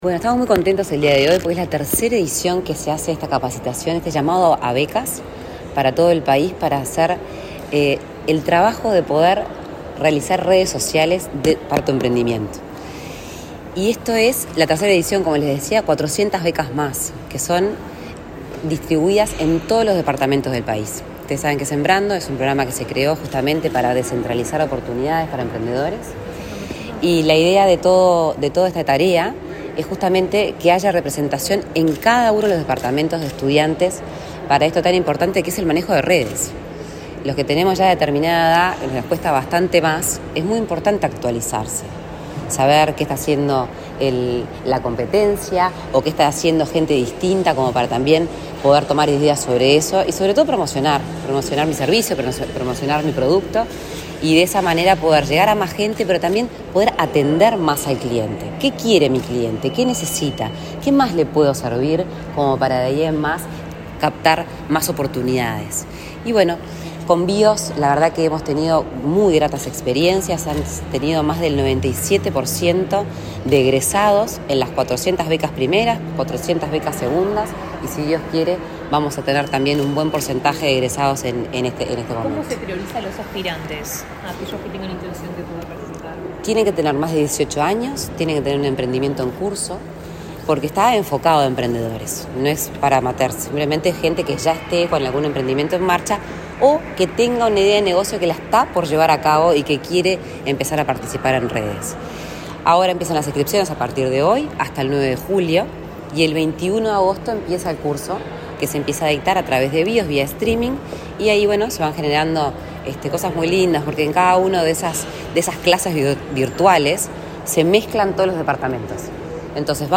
Declaraciones a la prensa de la impulsora de Sembrando
La impulsora de Sembrando, Lorena Ponce de León, dialogó con la prensa luego de participar en el lanzamiento de la tercera edición del programa Redes